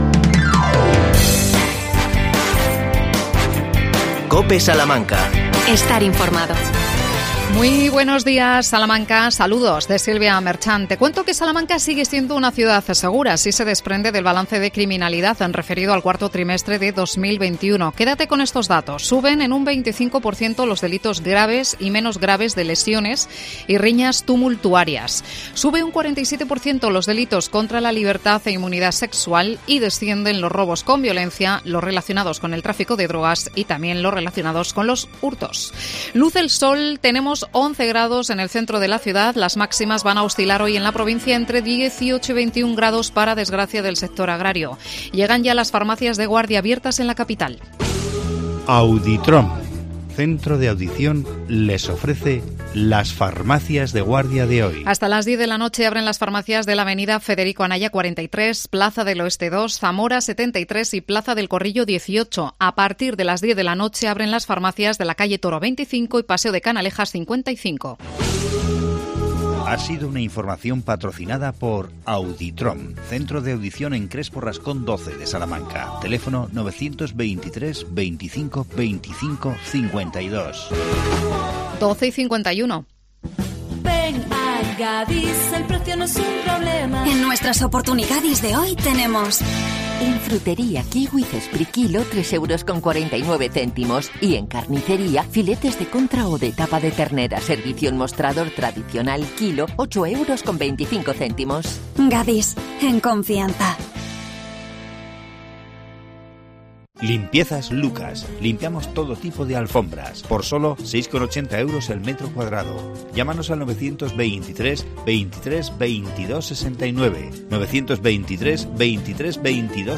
AUDIO: Entrevistamos a Isabel Macías, concejala de Mayores. El tema: talleres de sensibilización a escolares.